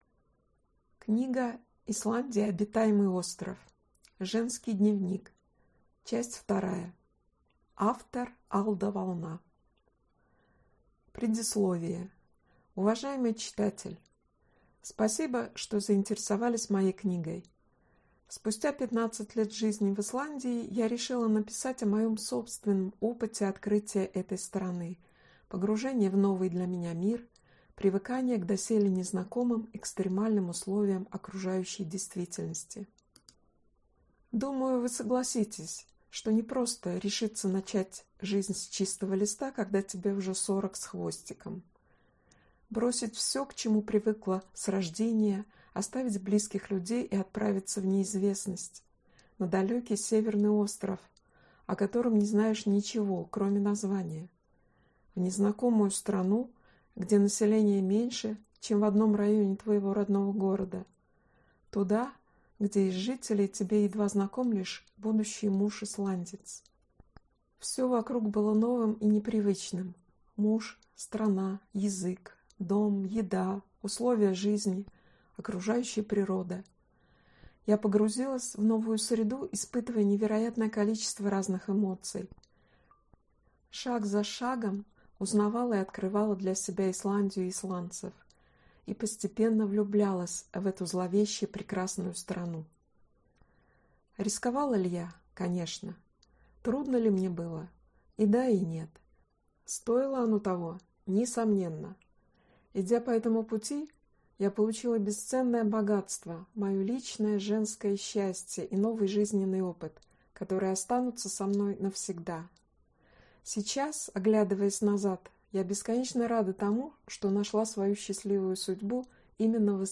Аудиокнига Исландия – обитаемый остров. Женский дневник 2 | Библиотека аудиокниг